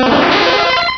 Cri de Staross dans Pokémon Rubis et Saphir.